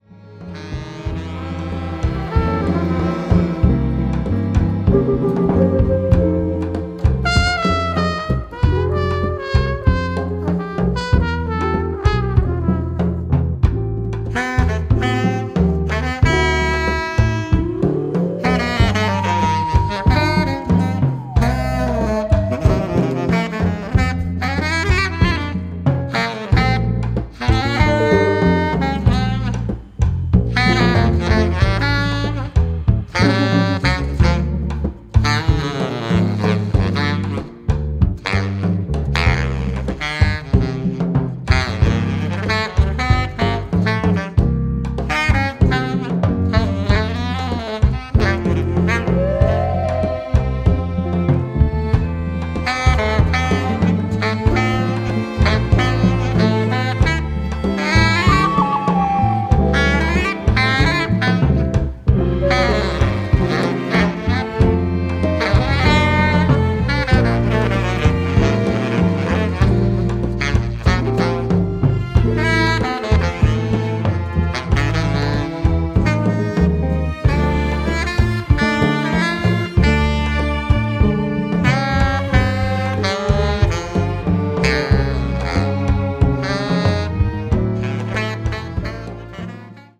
リラクシンなテンションで優しく温かみのあるサウンドを紡ぎ出していく
全編通して温かく豊かな味わいに満ちた仕上がりとなっています。